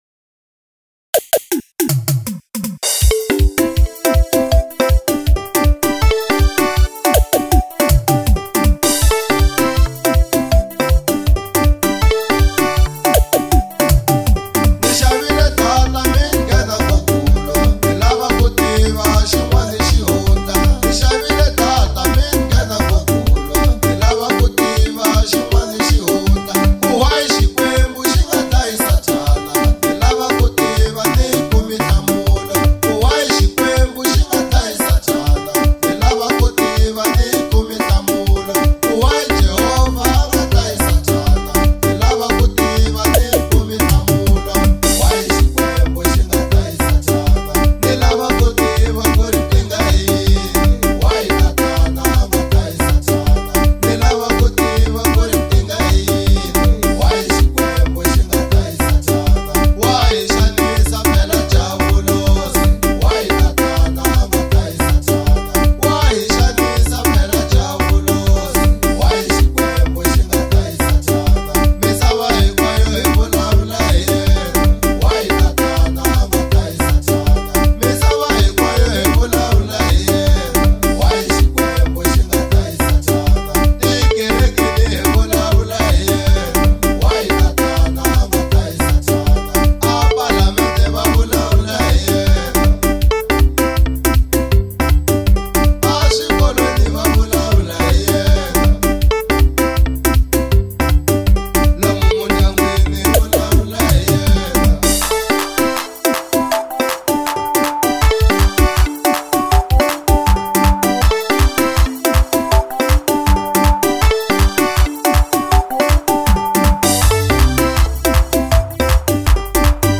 05:33 Genre : Xitsonga Size